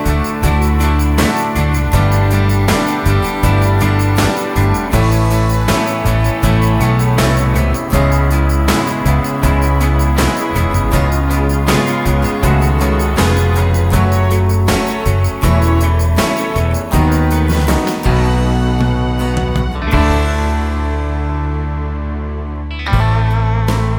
Medley Pop (1990s)